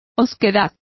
Complete with pronunciation of the translation of surliness.